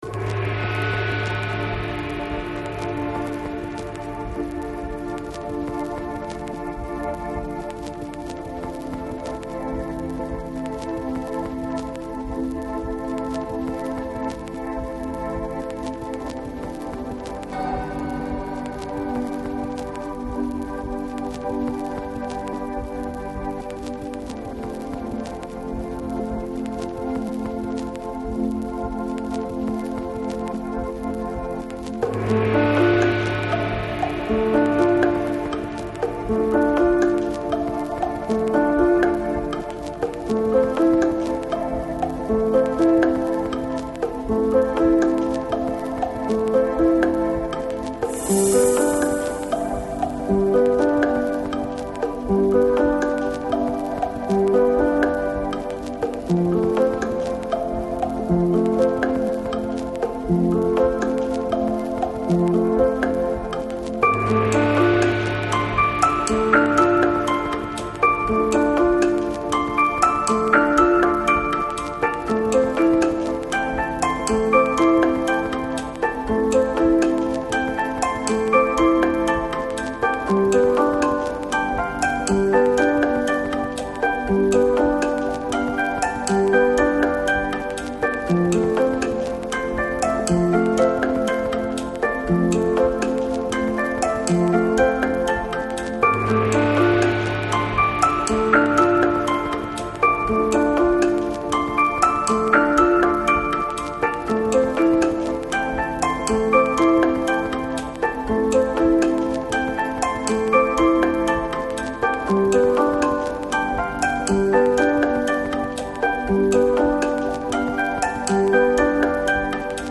Lounge, Chill Out, Downtempo, Ambient, World